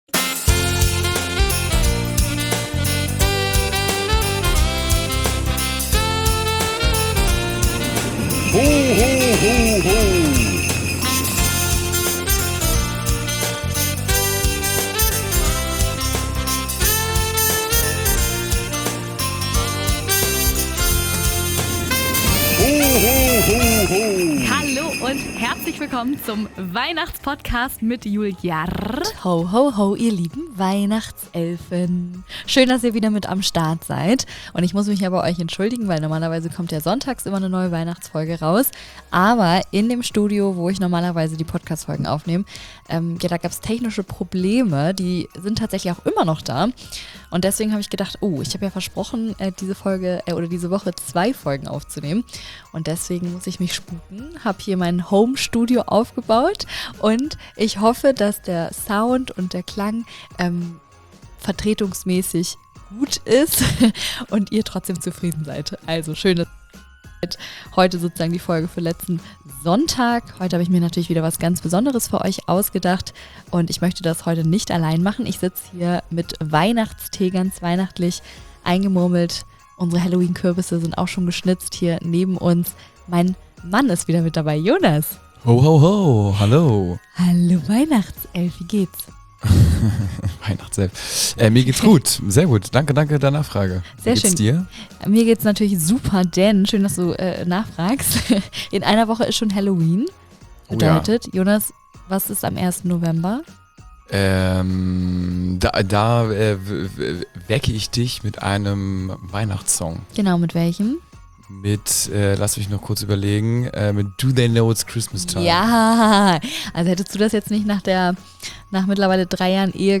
Der Live Auftritt Pt.2
Die zweite Halbzeit des Live Auftritts. Hier handelt es sich um Weihnachts Fails.